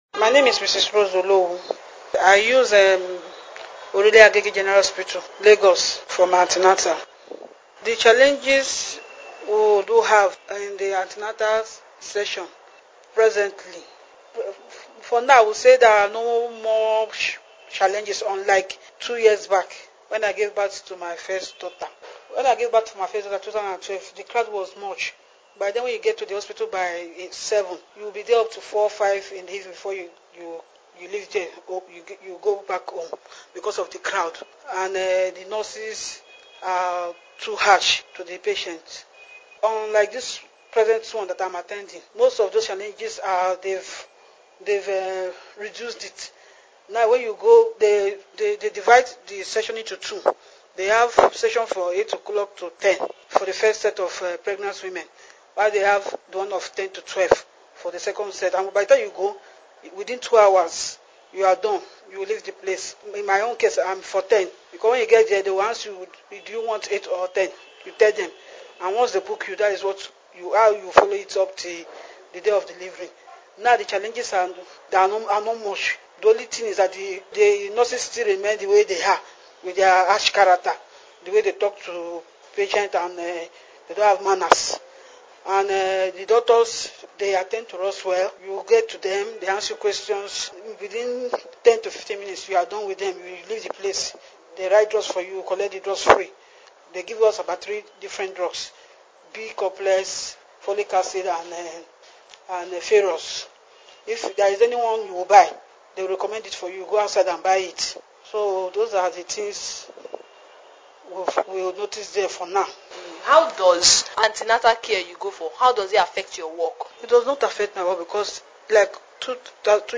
This interview is about the challenges women face in accessing maternal care in Lagos, the most populated city in Nigeria with a population of about 20 million people.